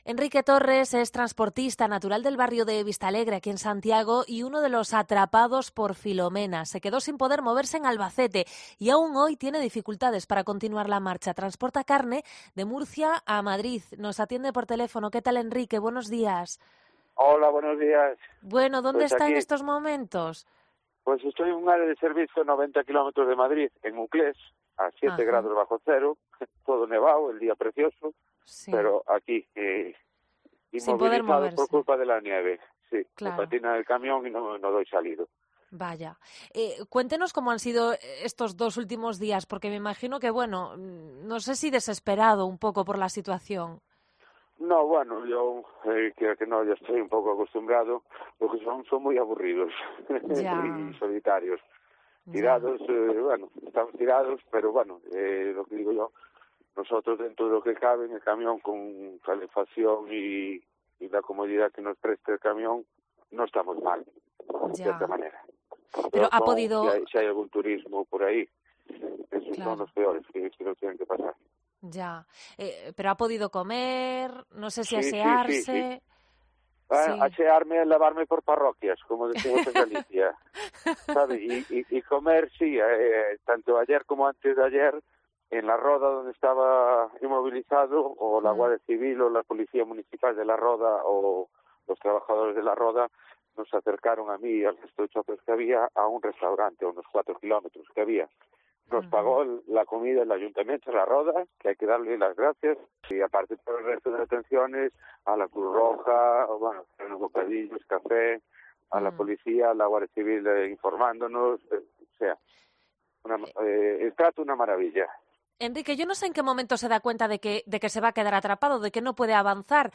A 7 grados bajo cero atendía la llamada de la COPE y con la pierna "enterrada en la nieve, hasta la rodilla".